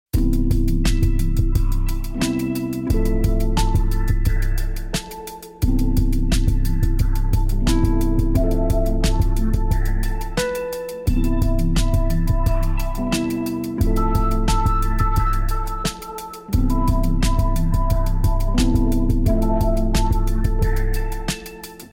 3D printed spiral ejection ball